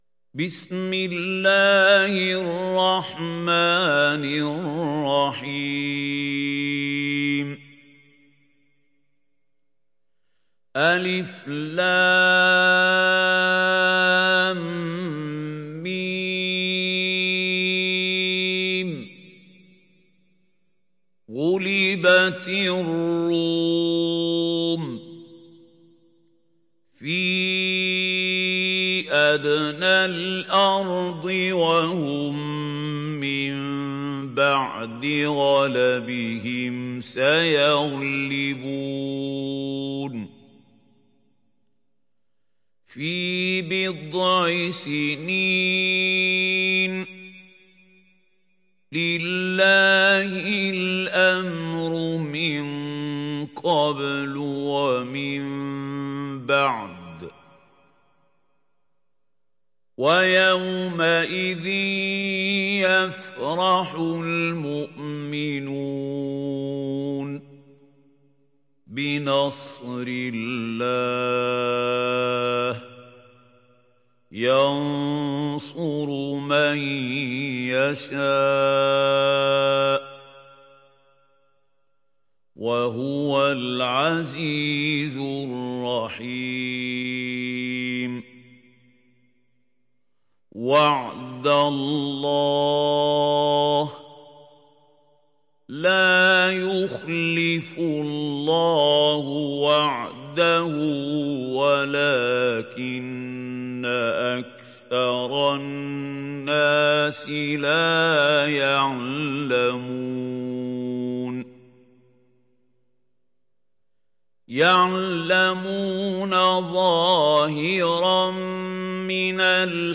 Rum Suresi mp3 İndir Mahmoud Khalil Al Hussary (Riwayat Hafs)